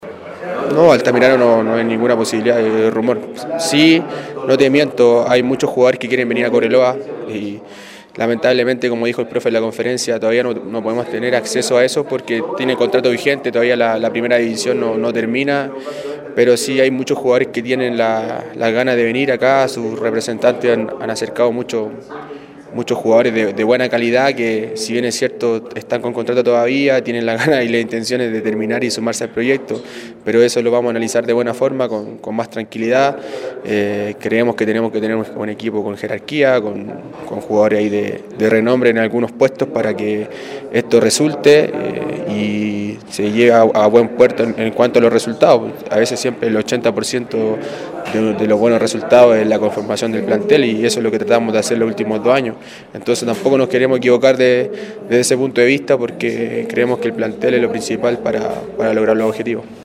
En diálogo con En La Línea